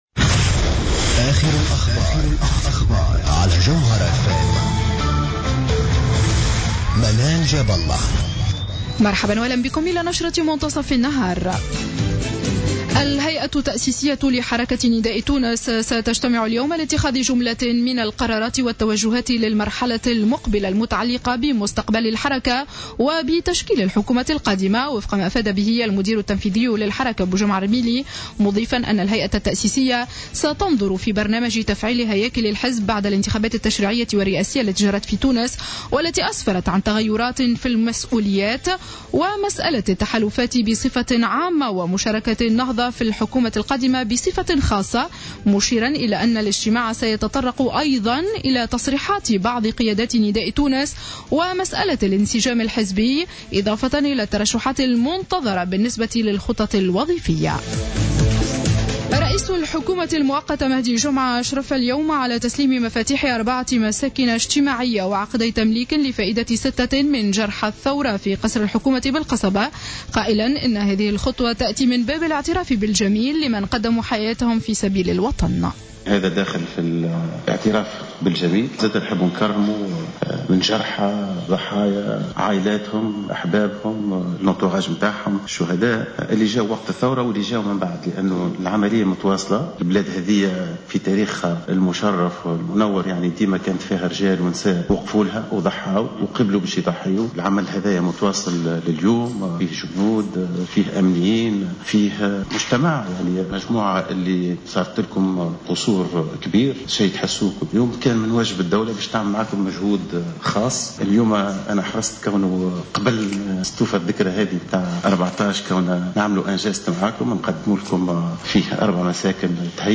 نشرة أخبار منتصف النهار ليوم الثلاثاء 13-01-15